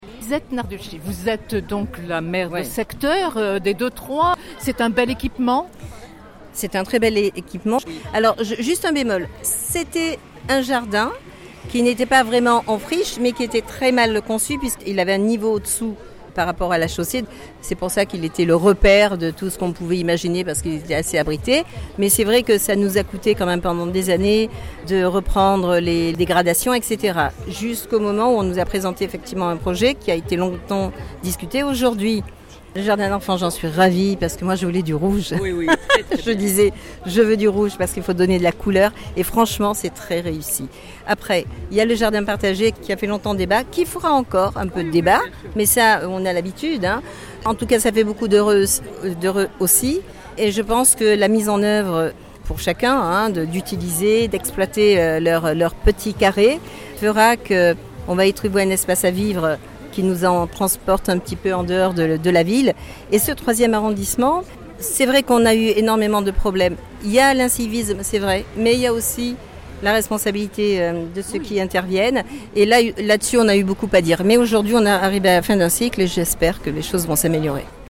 Au 14, rue de Ruffi dans le 3e arrondissement de Marseille, entre plants de fraises et barbe à papa, les habitants du quartier se pressaient pour cette fête de quartier organisée pour l’inauguration des Jardins de Ruffi à la fois jardin d’enfant et jardins partagés.